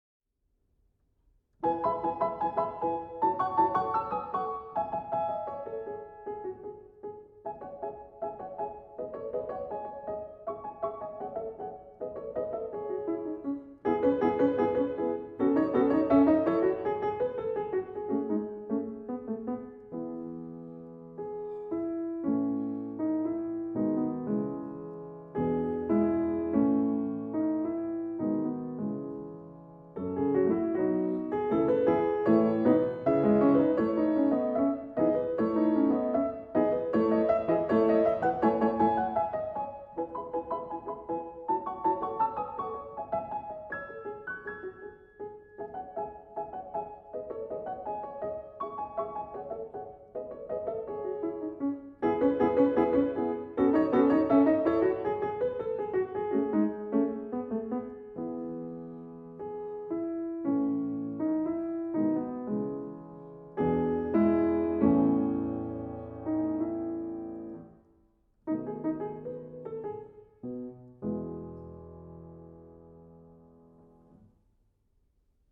For Piano. Bagatelle (Moderato)